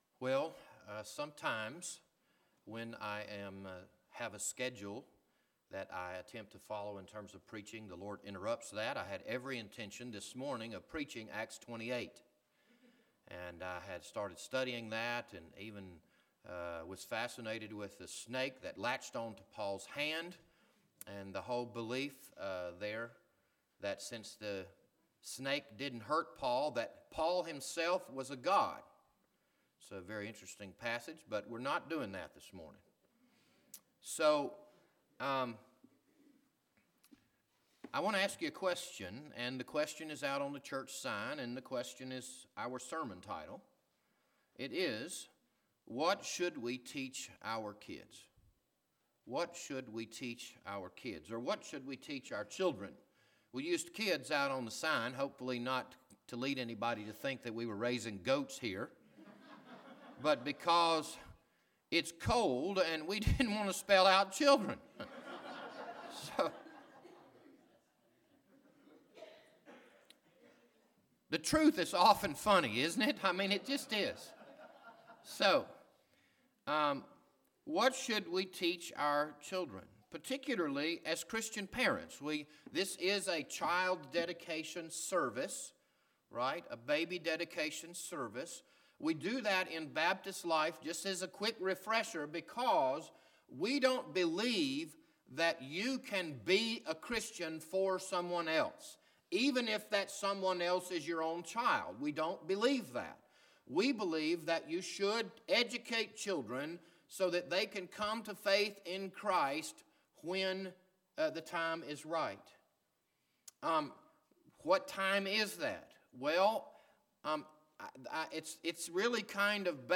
This Sunday evening sermon was recorded on January 21, 2018.